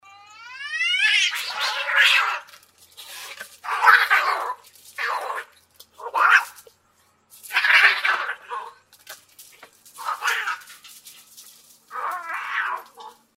Кошачья драка до крови